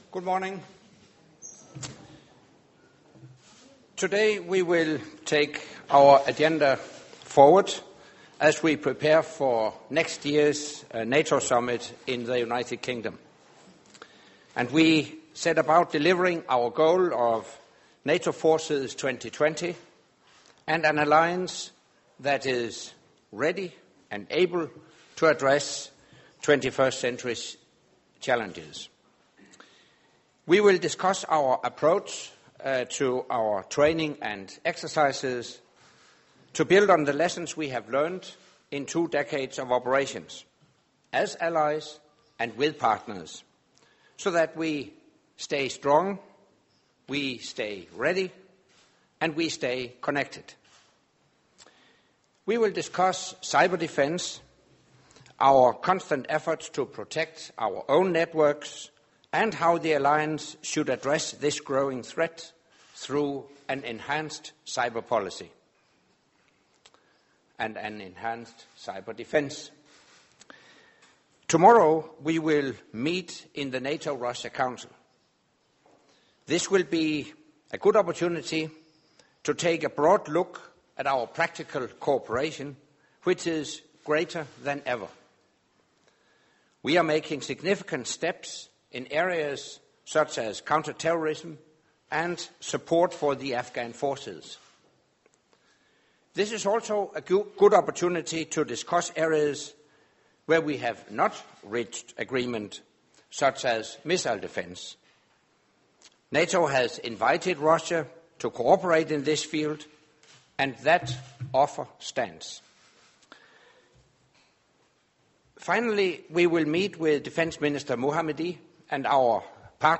Doorstep Statement by the NATO Secretary General, Anders Fogh Rasmussen at the start of the NATO Defence Ministers meeting in Brussels